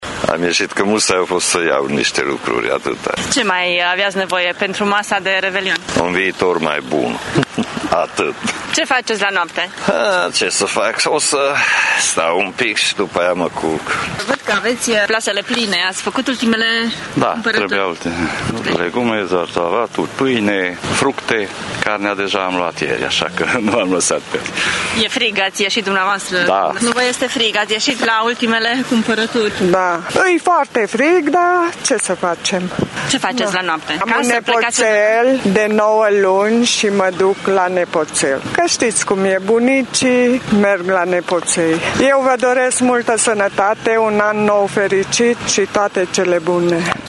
Oamenii au mai cumpărat produse din carne, legume și fructe pentru masa de Anul Nou și peră că în anul care v ine vor avea un trai mai bun: